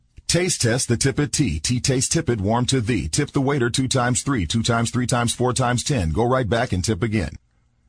tongue_twister_05_02.mp3